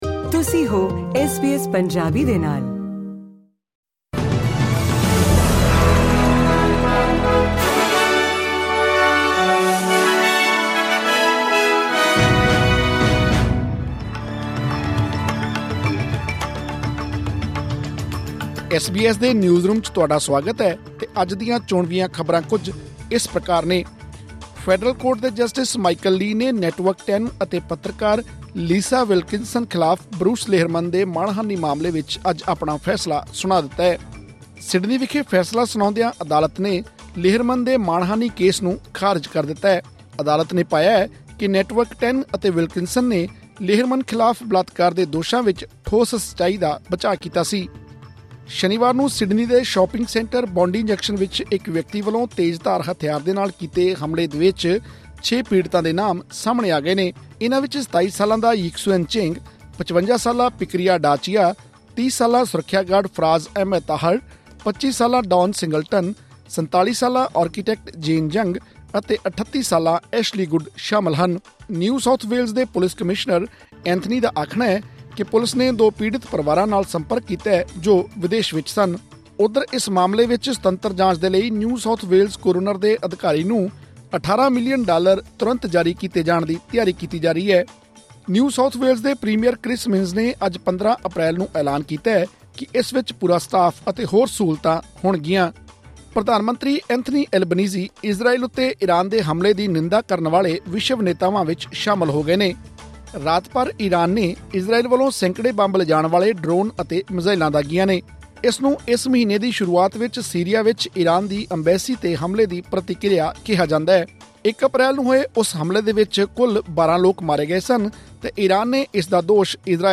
ਐਸ ਬੀ ਐਸ ਪੰਜਾਬੀ ਤੋਂ ਆਸਟ੍ਰੇਲੀਆ ਦੀਆਂ ਮੁੱਖ ਖ਼ਬਰਾਂ: 15 ਅਪ੍ਰੈਲ, 2024